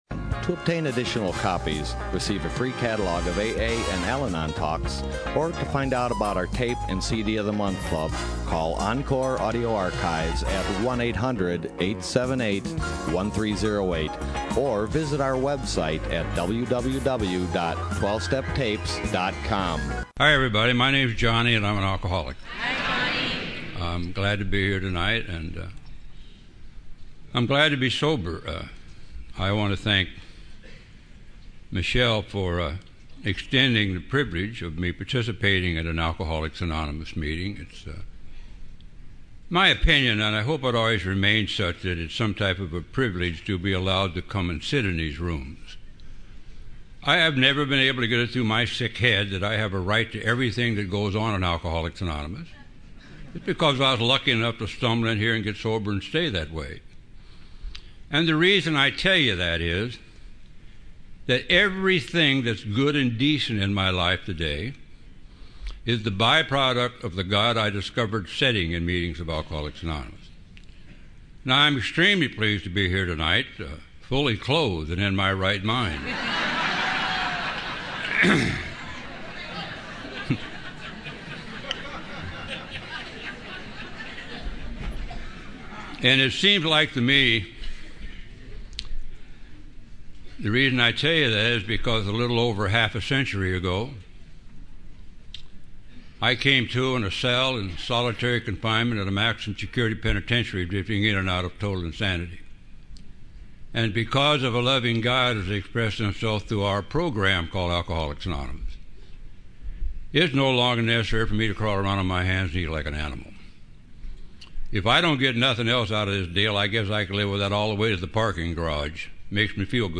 Encore Audio Archives - 12 Step Recovery Orange County AA Convention 2013